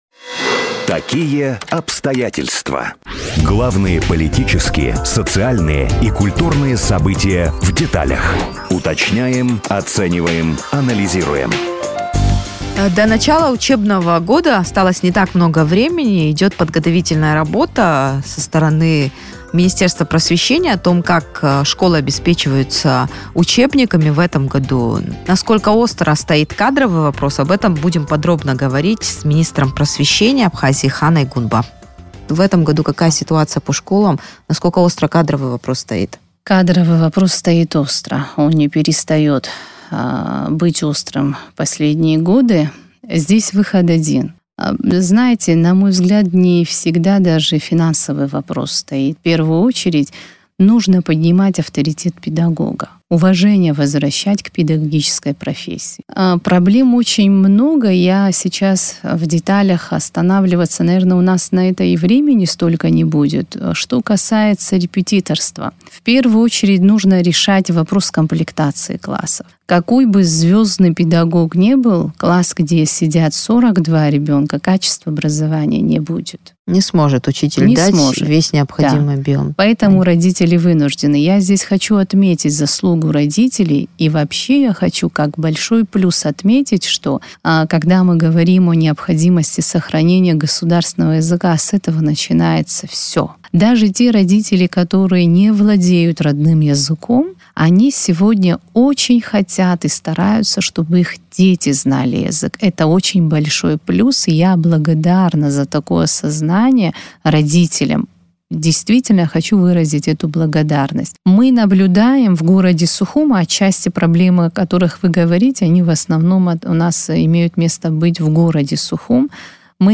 Педкадры, абхазский язык в школах, ситуация с учебниками. Интервью с министром просвещения
О проблеме нехватки педагогических кадров, переполненных школьных классах, нехватке учебников, о том, как поднять уровень изучения абхазского языка, в эфире радио Sputnik говорили с министром просвещения Ханой Гунба.